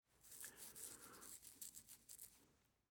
Тихое поглаживание бороды